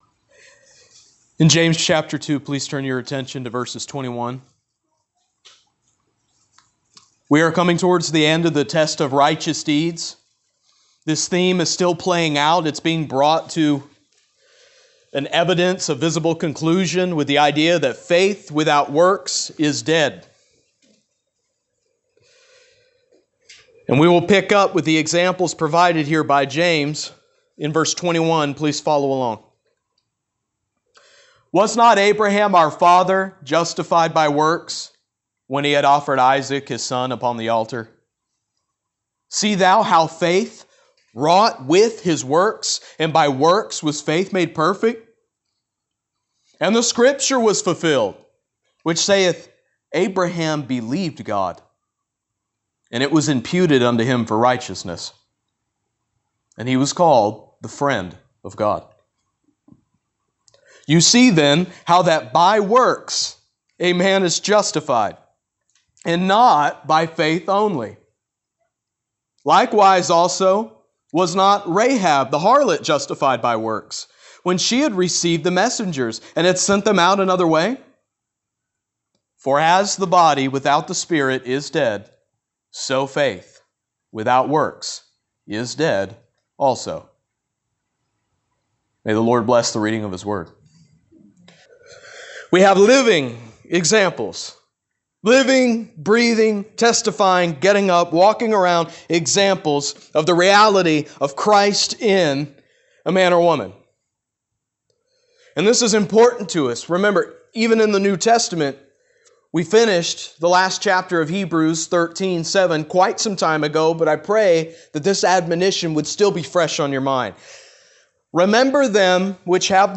Jm2 Pt 9 Experiment of Faith | SermonAudio Broadcaster is Live View the Live Stream Share this sermon Disabled by adblocker Copy URL Copied!